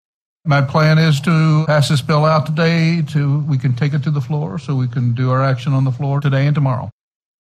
On Monday, the Missouri Senate Appropriations Committee began hearing House Bill 3, which would extend certain agricultural tax credits for six years.
Committee chair, Sen. Dan Hegeman (R-Cosby), spoke on the similarities with Senate Bill 8: